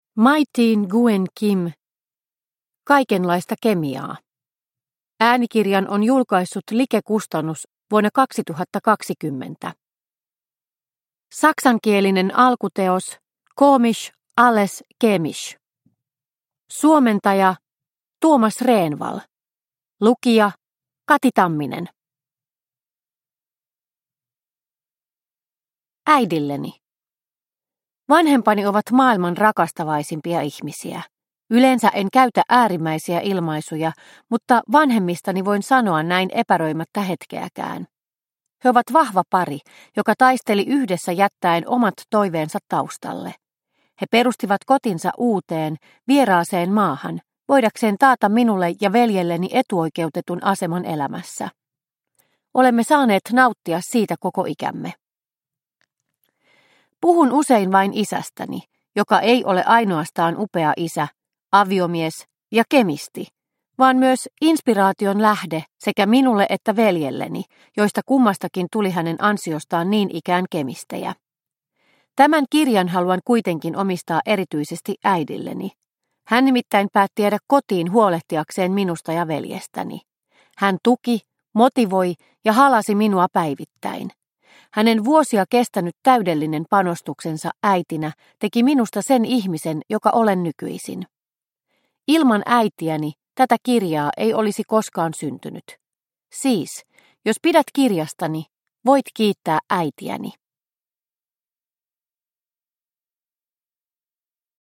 Kaikenlaista kemiaa – Ljudbok – Laddas ner